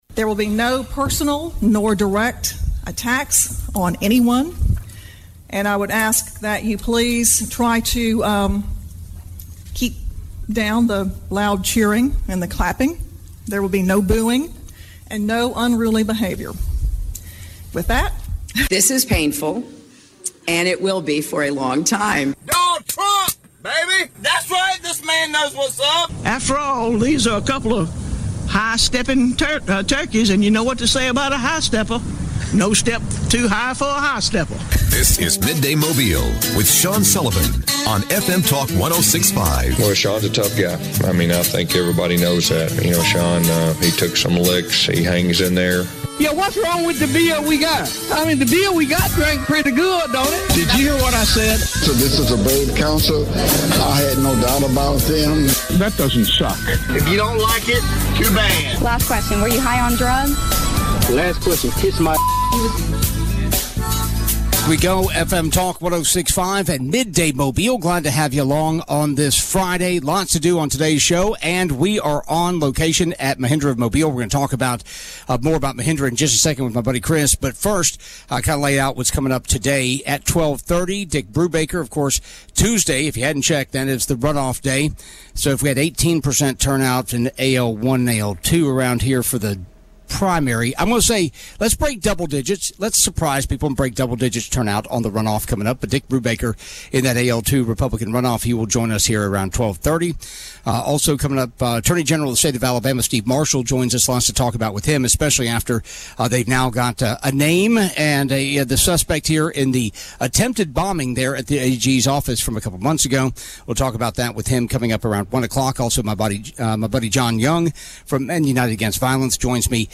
Midday Mobile - Hour 1 LIVE from Mahindra of Mobile featuring Dick Brewbaker - April 12 2024